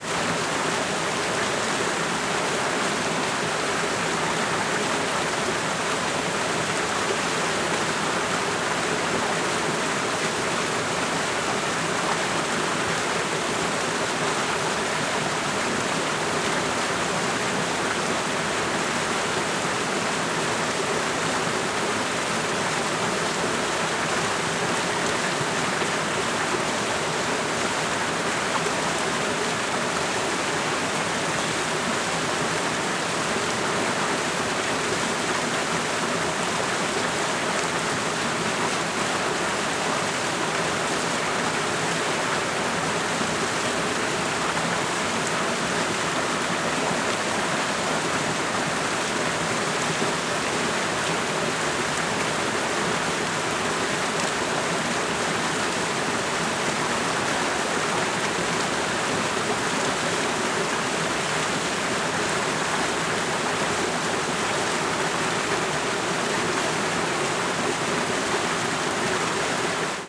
CSC-01-034-GV - Pequena queda dagua entre muitas pedras.wav